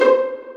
tock.mp3